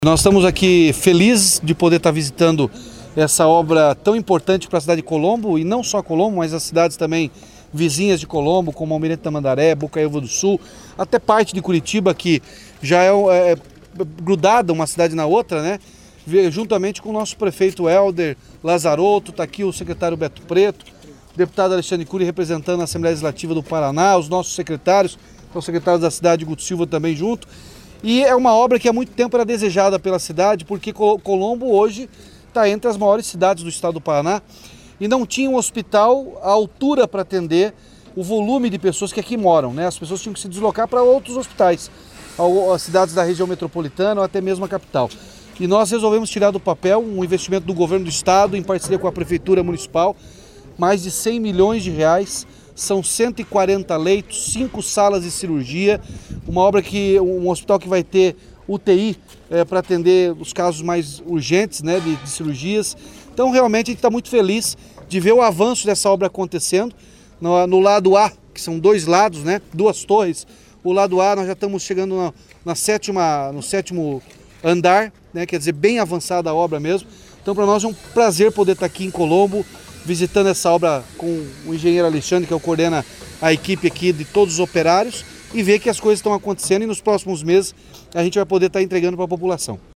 Sonora do governador Ratinho Junior sobre a vistoria das obras do novo Hospital Geral de Colombo, na Grande Curitiba